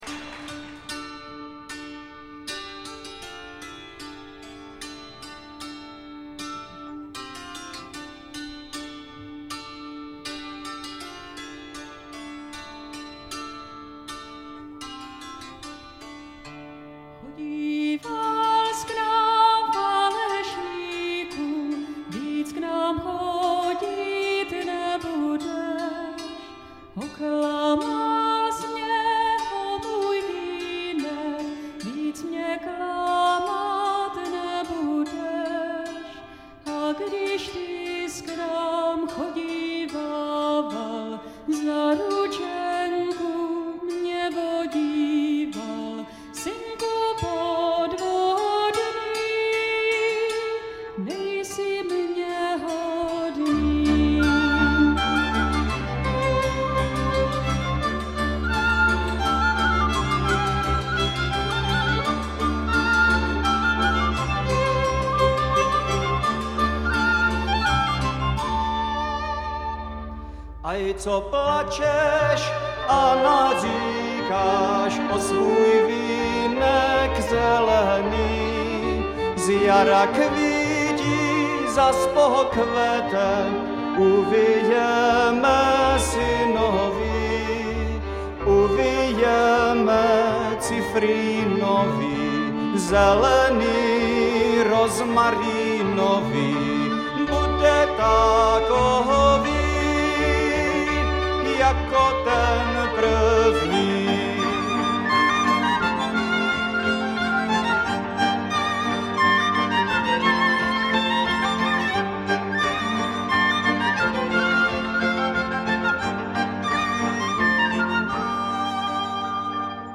lidová, úpr. Jiří Pavlica, zp.